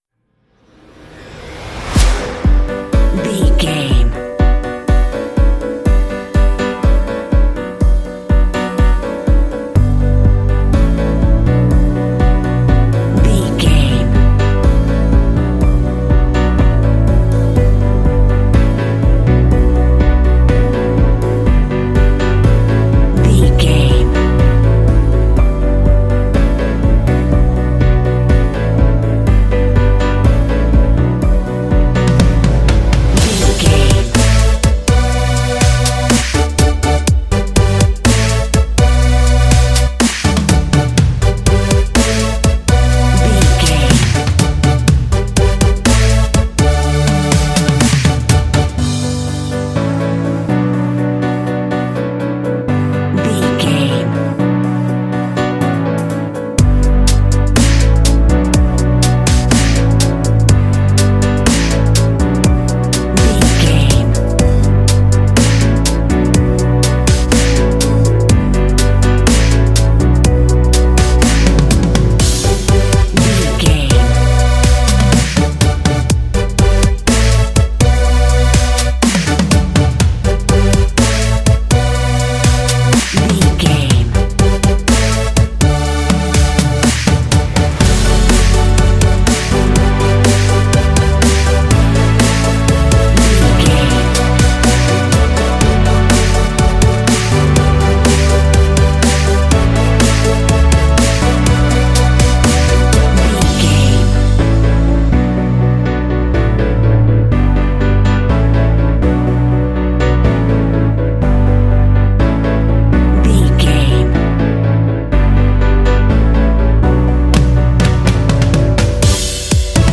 This track makes for a groovy ambient underscore.
Uplifting
Aeolian/Minor
bright
joyful
piano
electric piano
drums
synthesiser
techno